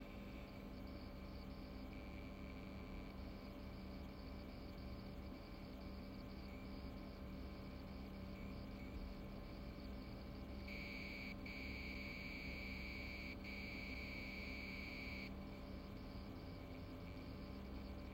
The sound of a 486SLC doing a SSH handshake.
Alt...Electronic noise from the CPU/RAM while handshaking.